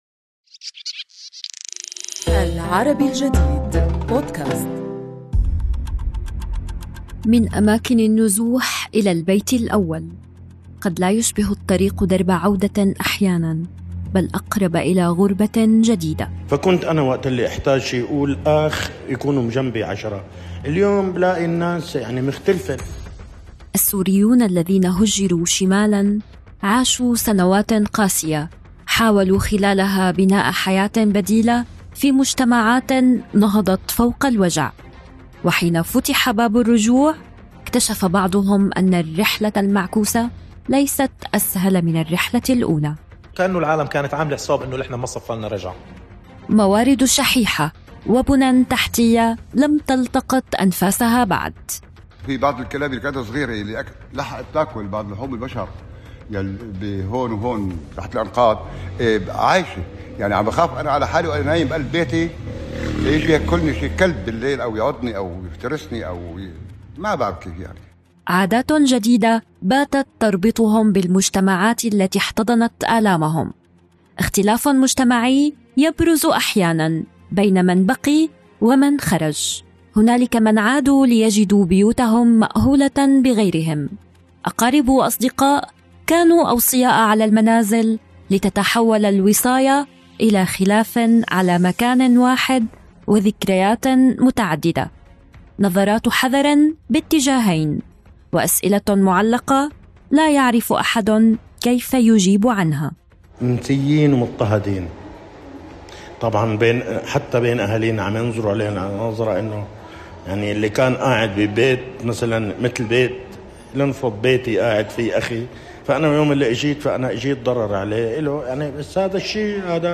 برنامج حواري، يناقش أحدث وأبرز الأخبار في الساحتين العربية والعالمية عبر لقاءات مع محللين وصحافيين وصنّاع قرار.